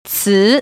[cí] 츠  ▶